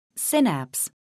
synapse : 1.시냅스   2.(신경 세포의) 연접(부)   미국 [sínæps]